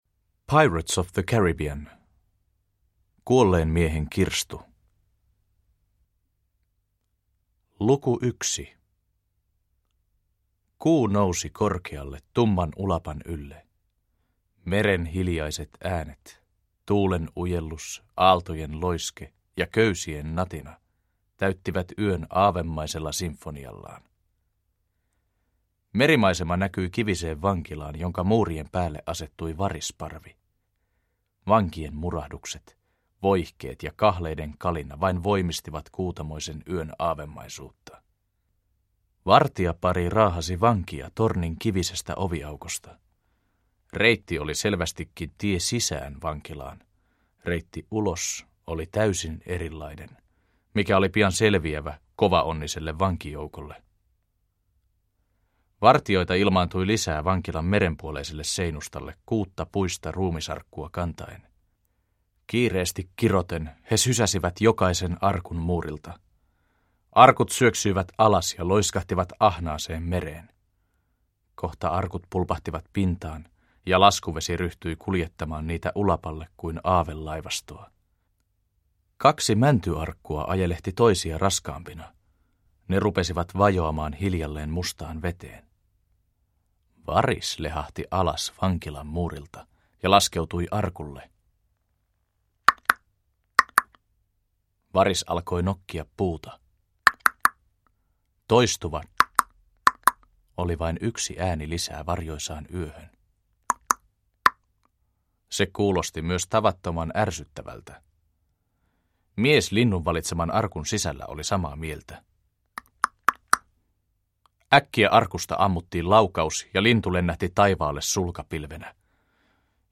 Kuolleen miehen kirstu – Ljudbok